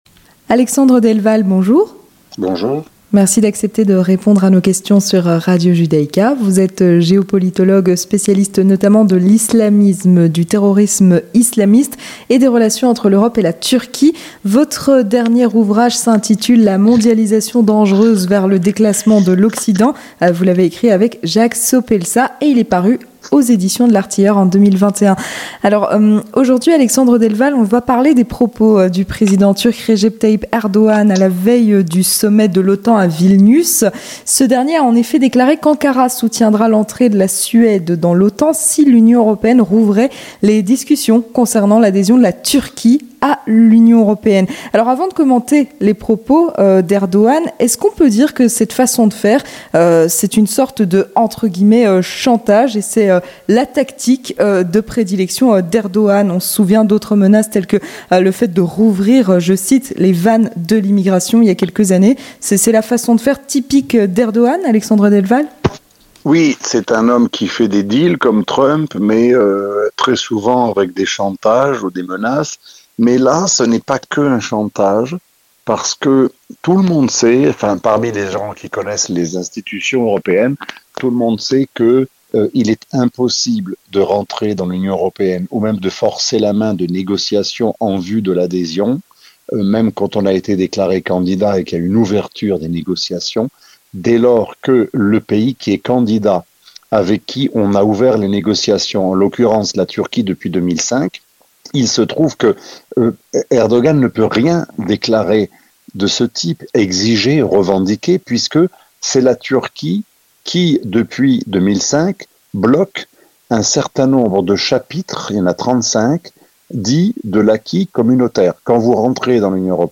Entretien du 18h - Les déclarations de Recep Tayyip Erdogan à la veille du sommet de l'OTAN à Vilnius